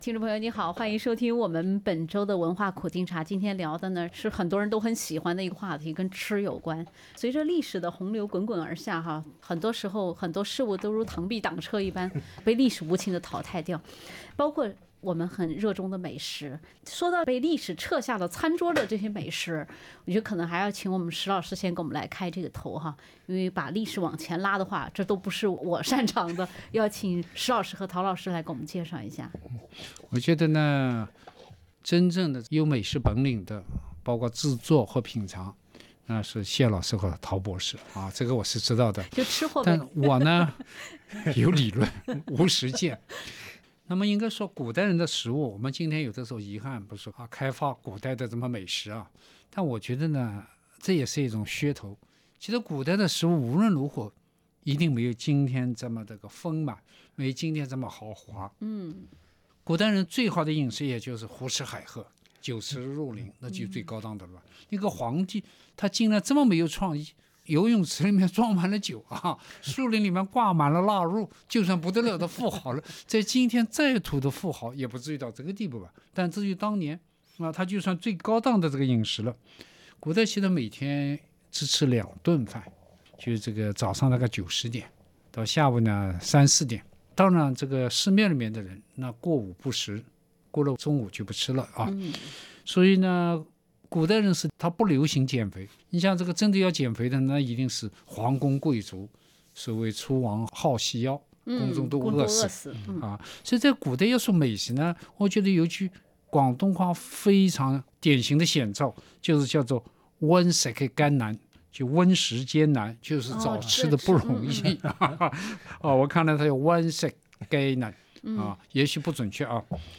平时很容易就达成共识的文苦三人组，这次因为蔡澜一句"火锅是最没文化的餐饮"而展开了辩论。
SBS电台《文化苦丁茶》每周五早上澳洲东部时间早上8:15播出，每周日早上8:15重播。